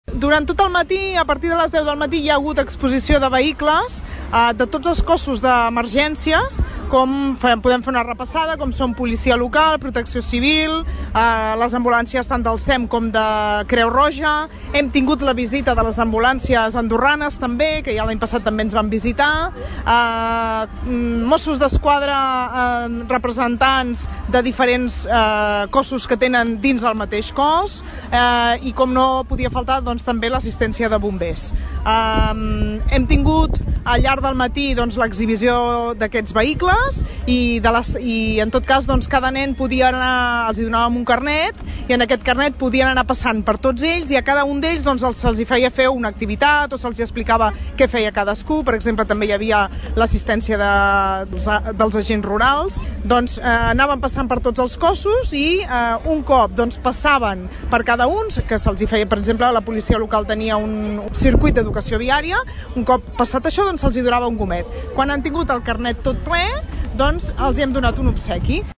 Per tal d’implicar als assistents, sobretot a la canalla, es va organitzar una gimcana d’activitats on cadascú havia d’omplir una targeta que requeria la visita i activitat amb cadascun dels cossos d’emergència que es trobaven al mateix recinte. Ho detalla Sílvia Català, regidora de l’Ajuntament de Tordera.